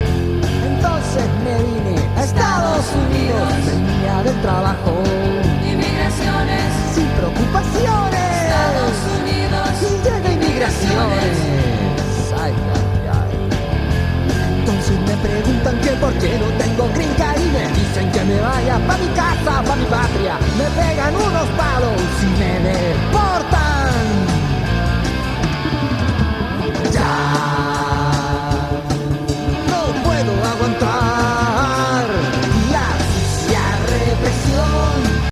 rock latino